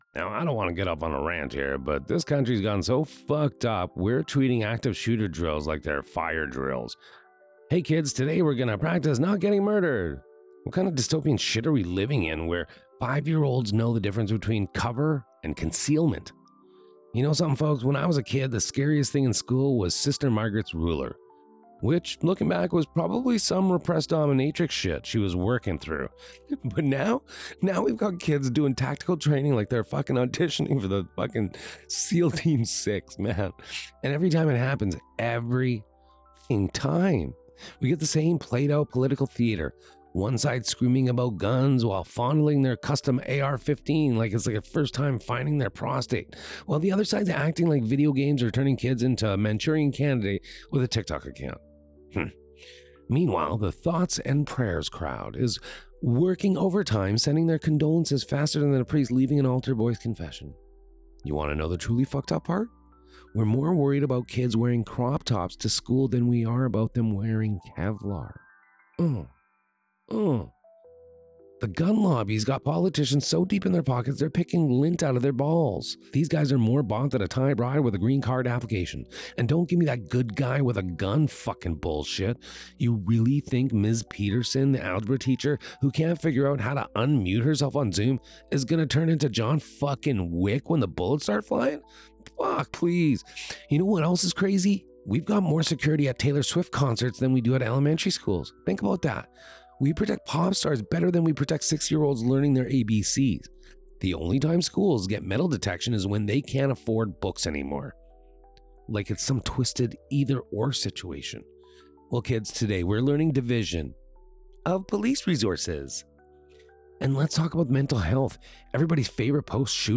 014-RANT.mp3